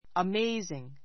amazing 中 əméiziŋ ア メ イズィン ぐ 形容詞 驚 おどろ くべき , 目を見張らせる an amazing sight an amazing sight 驚くべき光景 It is amazing that so many young people are doing volunteer work nowadays.